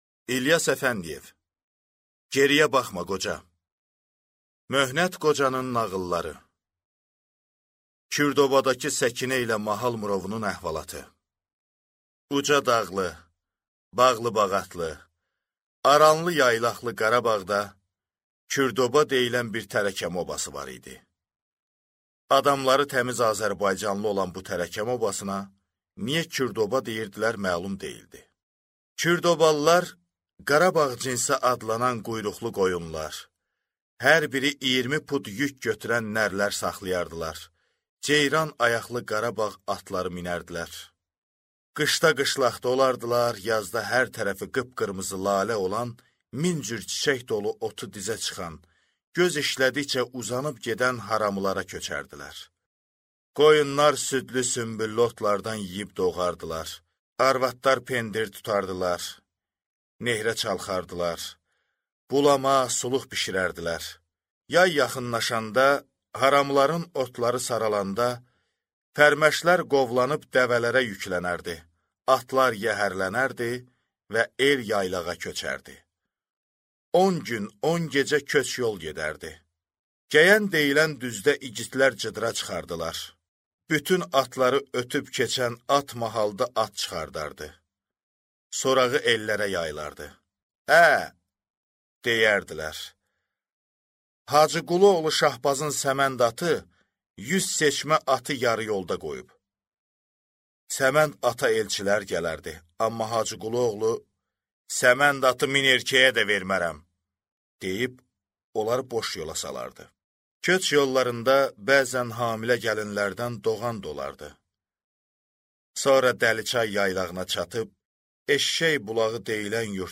Аудиокнига Geriyə baxma qoca | Библиотека аудиокниг